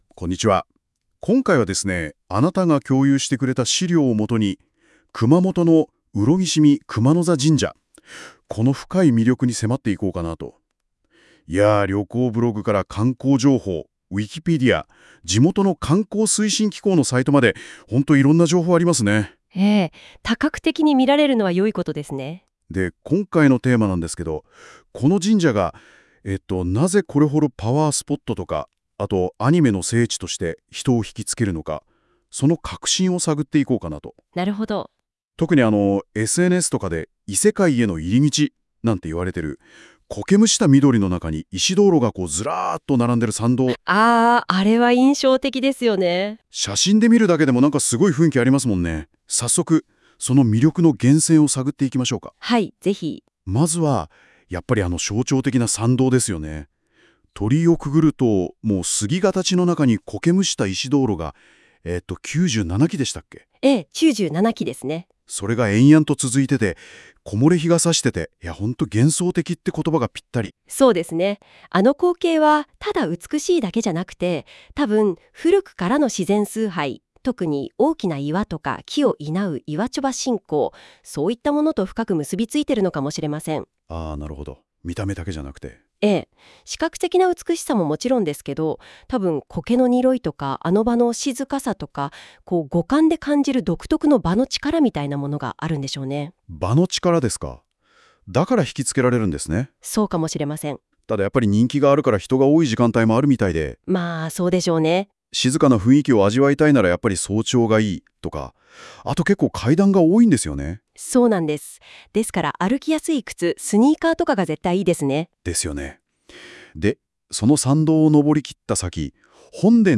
ラジオ風で楽しむ、上色見熊野座神社の魅力ガイド 上色見熊野座神社の解説音声